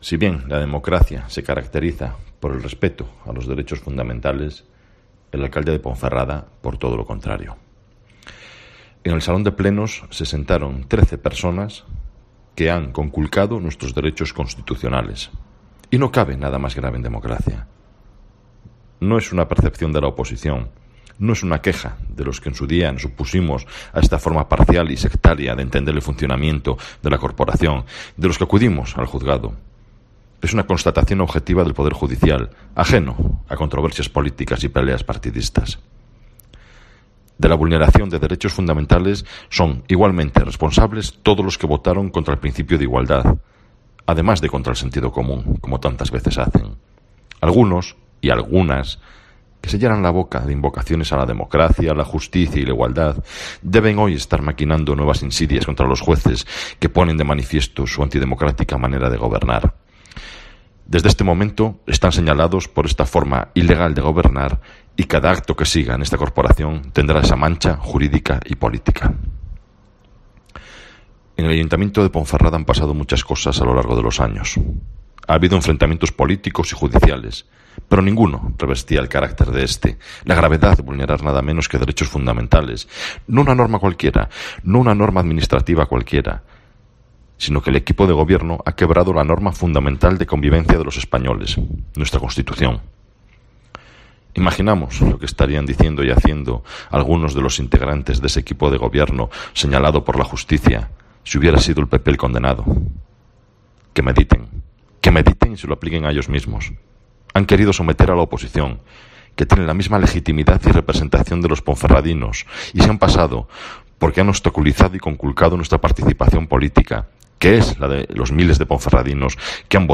Escucha aquí al portavoz del PP en el Ayuntamiento de Ponferrada, Olegario Ramón